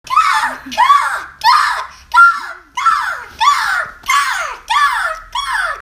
Seagull 2.wav